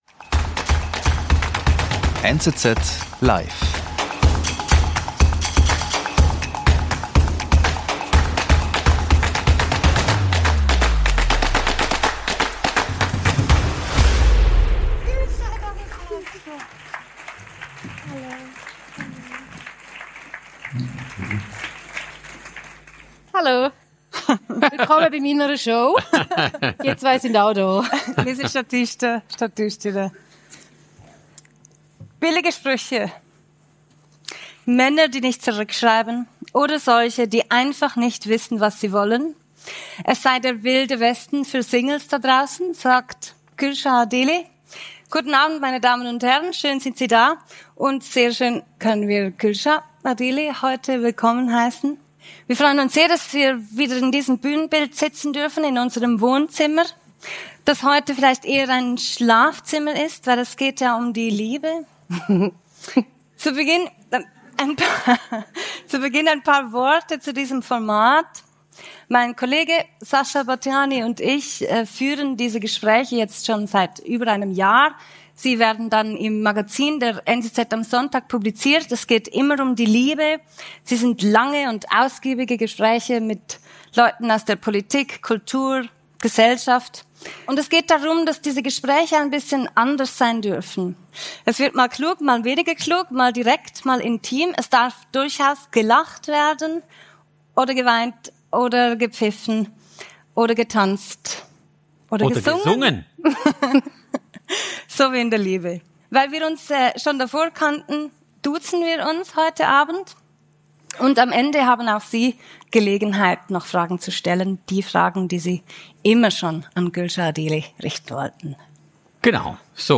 Die Schweizer Moderatorin, Podcasterin und Meisterin des unverblümten Wortes berichtet offen, charmant und mit mehr als einer Prise Ironie über ihre eigenen Erfahrungen mit der Liebe und dem Dating.